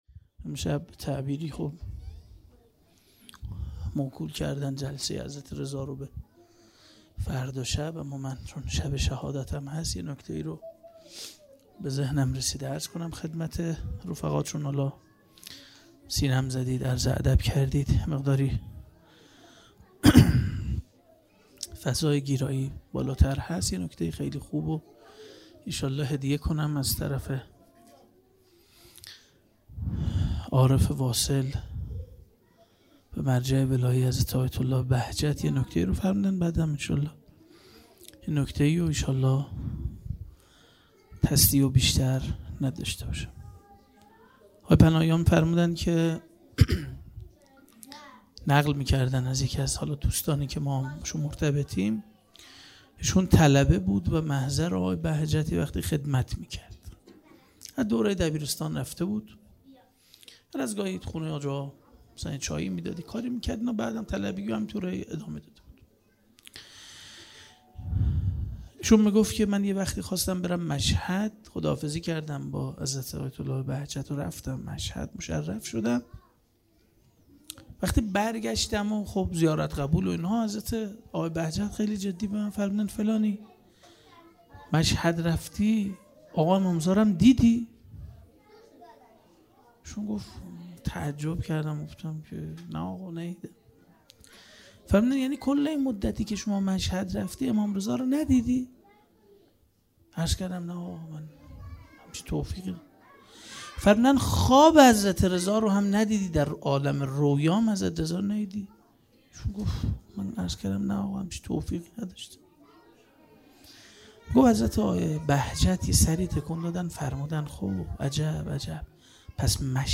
خیمه گاه - هیئت بچه های فاطمه (س) - سخنان پایانی